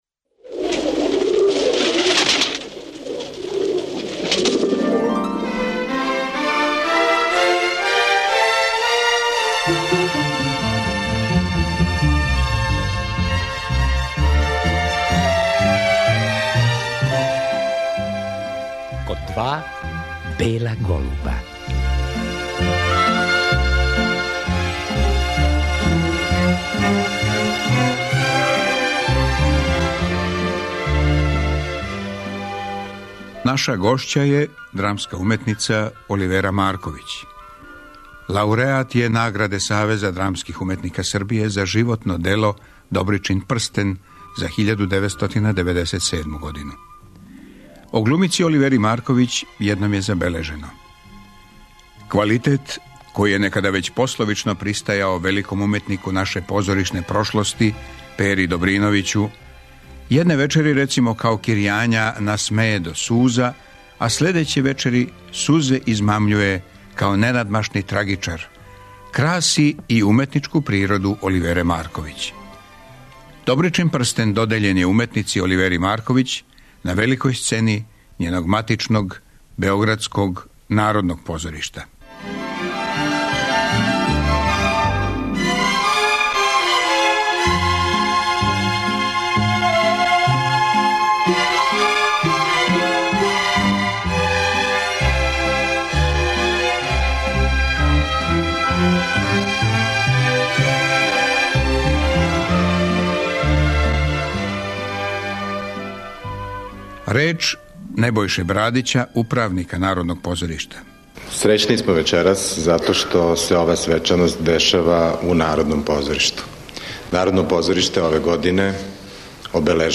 На вест да је преминула Оливера Марковић, позоришна, филмска и телевизијска глумица, емитујемо репризу емисије 'Сећања Оливере Марковић' из 1998.